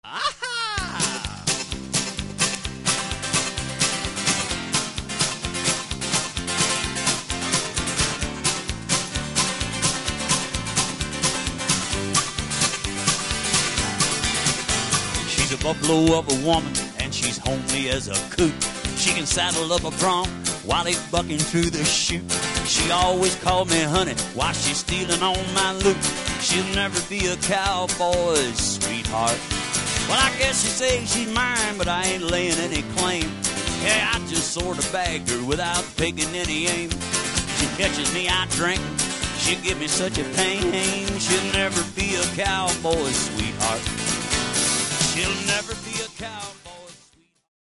Recorded LIVE in Deadwood, South Dakota